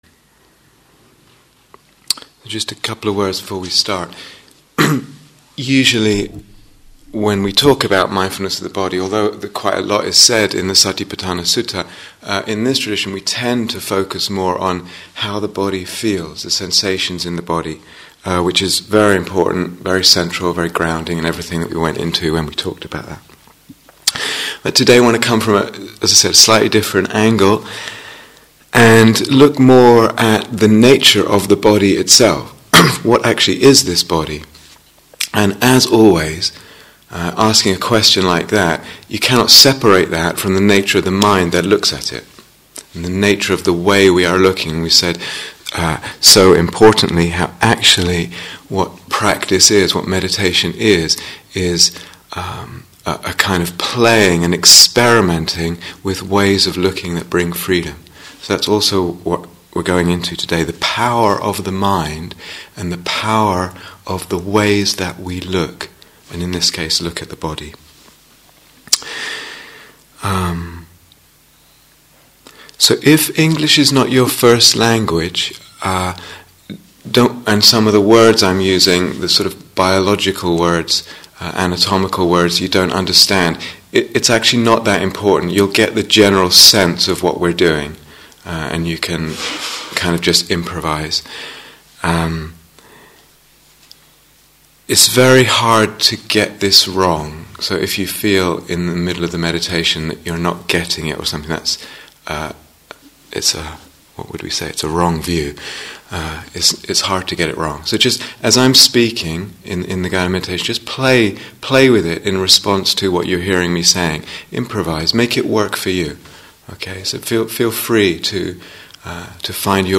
Body/Image - A Guided Meditation